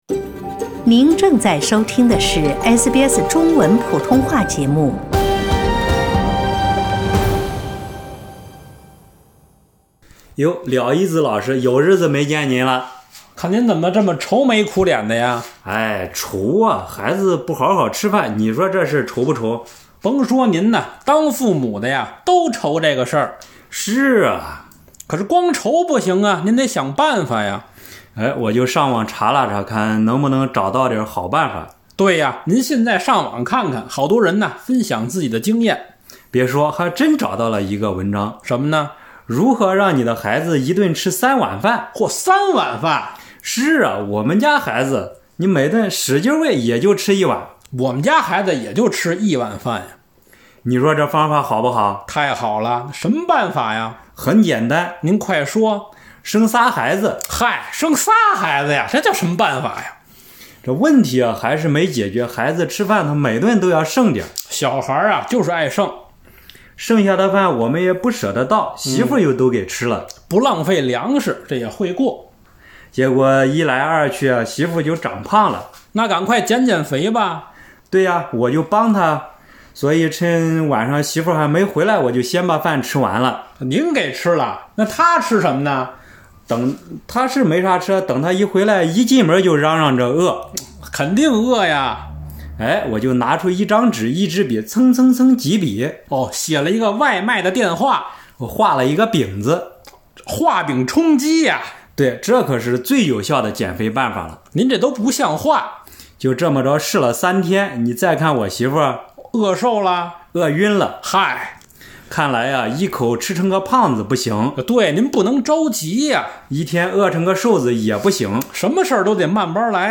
相声作品：长寿的秘诀
虽然两位朋友都暂时不是专业相声演员，但他们的这段相声也挺有意思的。